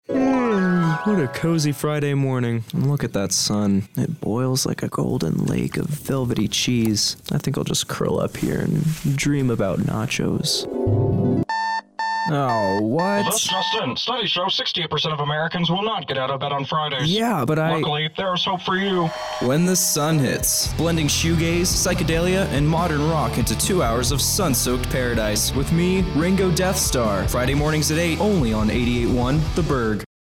Best Audio Promo
Best-Promo.mp3